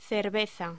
Locución: Cerveza
voz